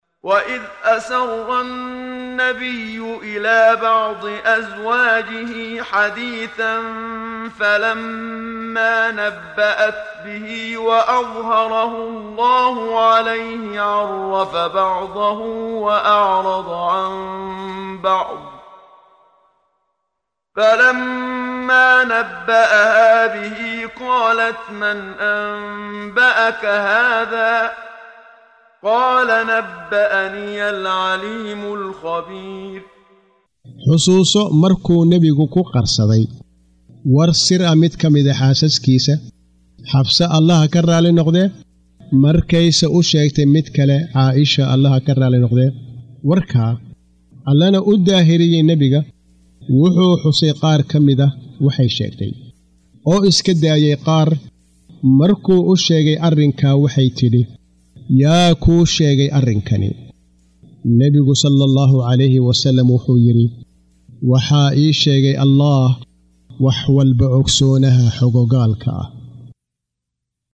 Waa Akhrin Codeed Af Soomaali ah ee Macaanida Surah At-Taxriim ( Iska xaaraantimeynta ) oo u kala Qaybsan Aayado ahaan ayna la Socoto Akhrinta Qaariga Sheekh Muxammad Siddiiq Al-Manshaawi.